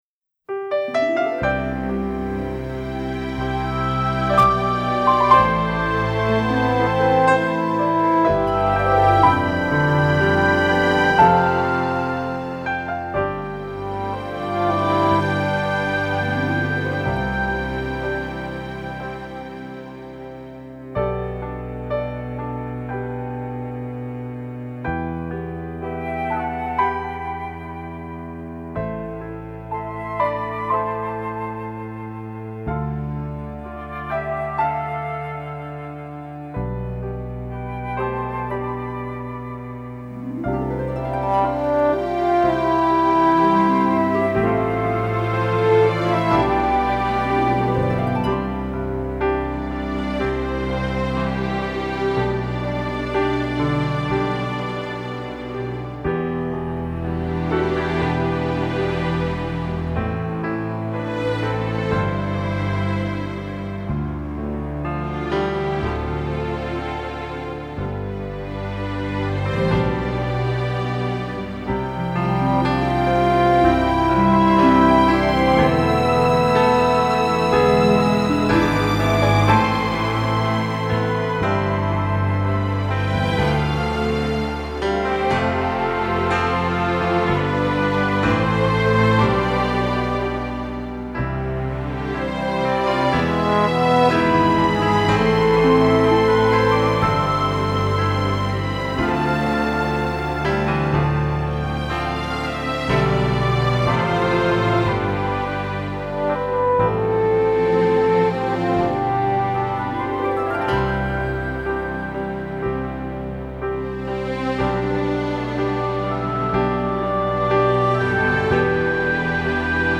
Образец исполнения: